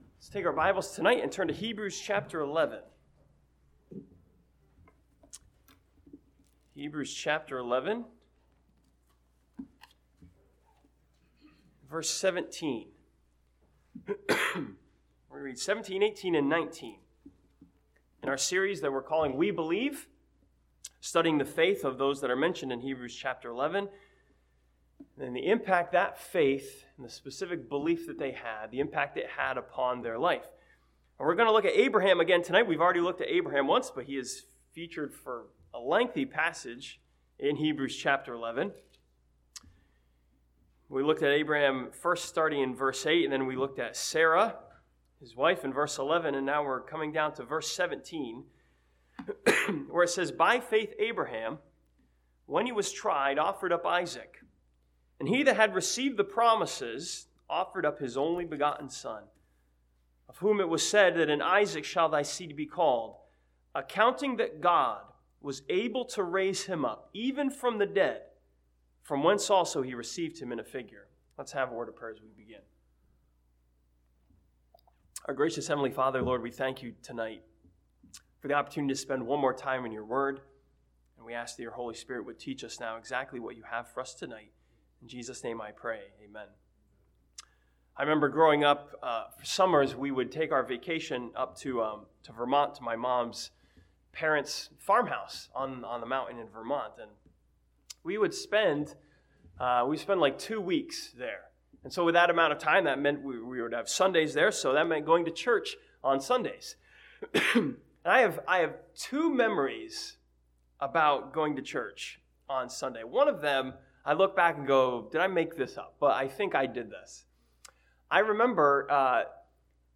This sermon from Hebrews chapter 11 continues the series We Believe by studying Abraham's faith in the power of God to raise the dead.